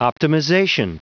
Prononciation audio / Fichier audio de OPTIMIZATION en anglais
Prononciation du mot : optimization